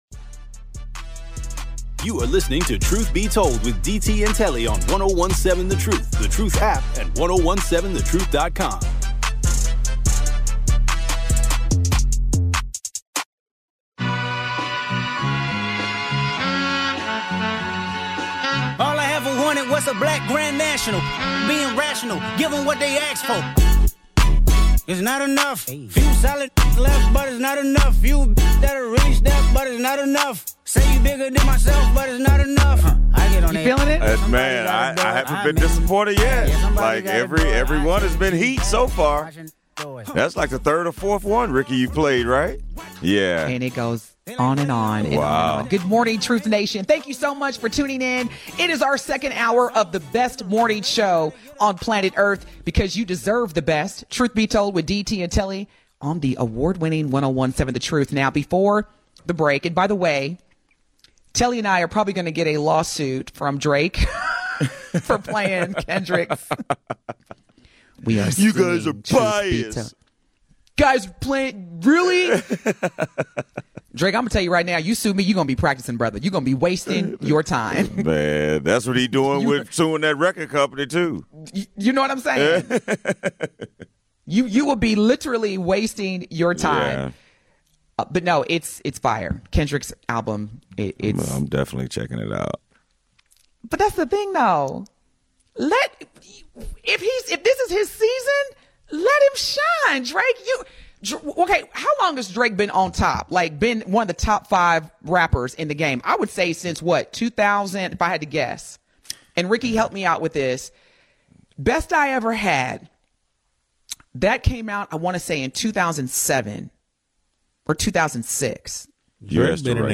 Is this a stroke of genius to protect American businesses, or a risky gamble with global markets? Tune in as they debate the impact on everyday Americans, its potential effects on inflation, and whether this is a savvy strategy or political posturing. Buckle up for a lively conversation filled with facts, opinions, and laughs along the way!